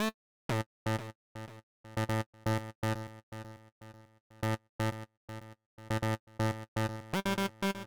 ORG Trumpet Riff A-G.wav